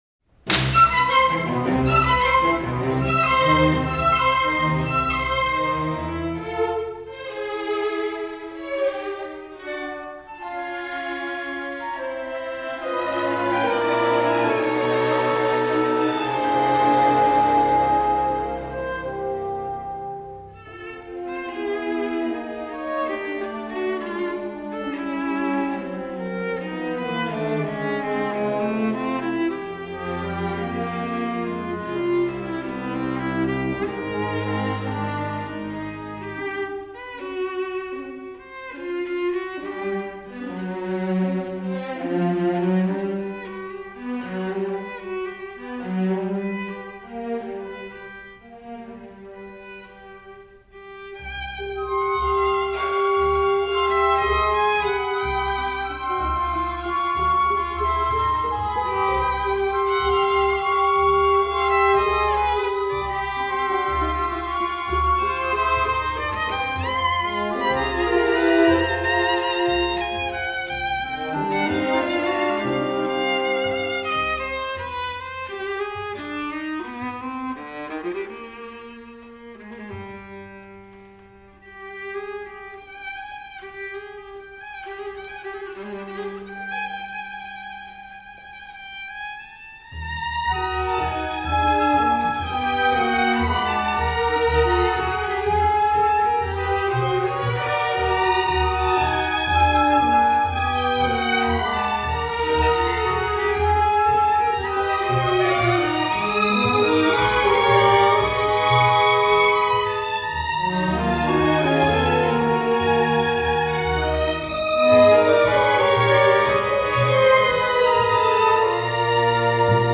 Category: Socially-Distanced Music, Concerti, Masterworks
Ensemble: Full Orchestra, Chamber Orchestra
Instrument / Voice: Viola